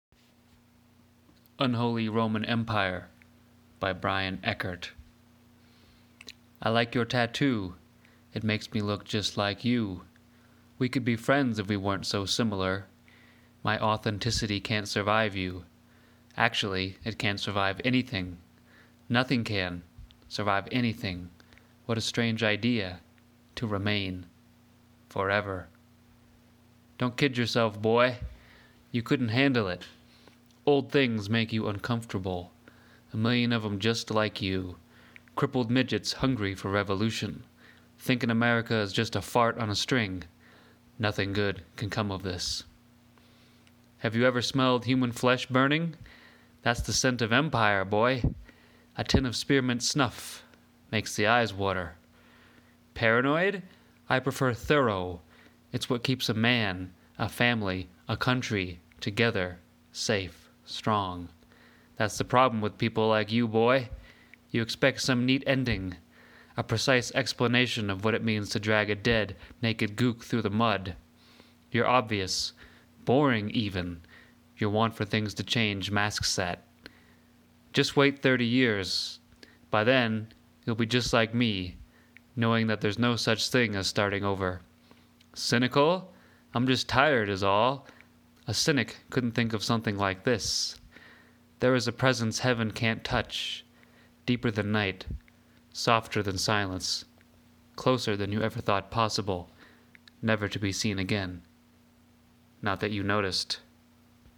reading